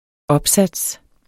Udtale [ -ˌsads ]